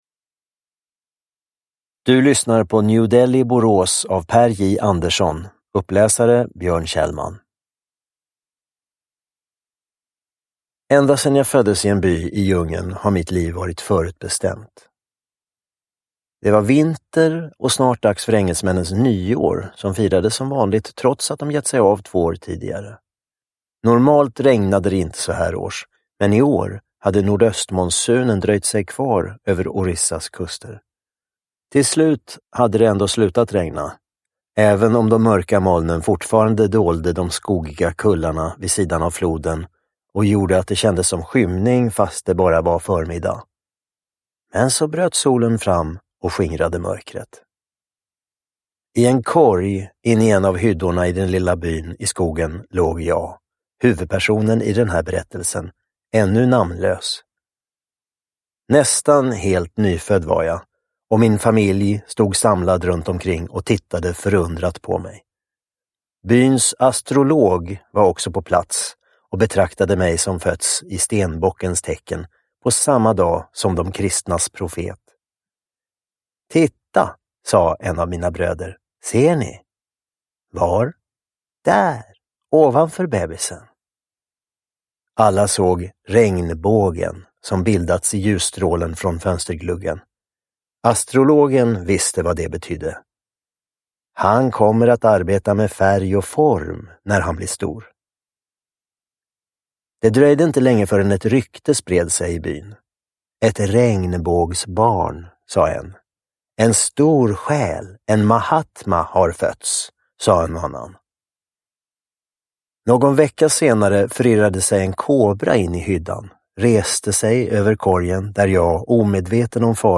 Uppläsare: Björn Kjellman
Ljudbok